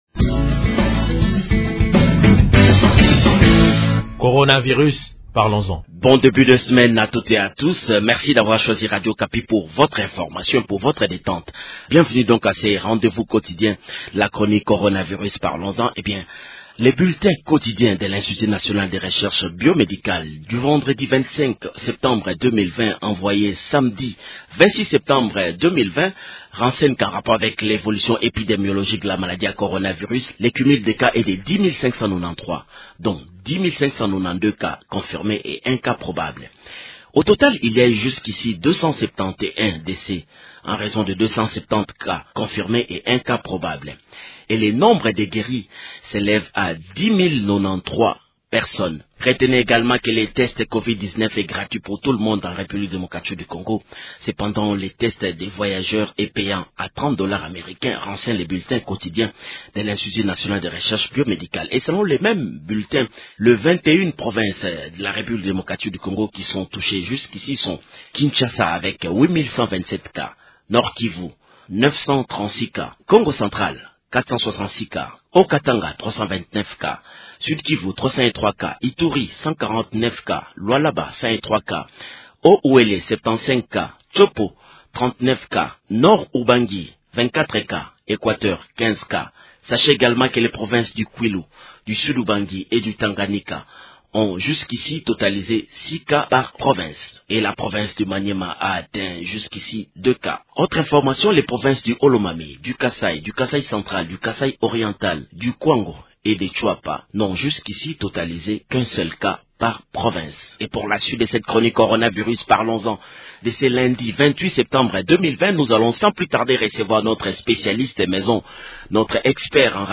Actualité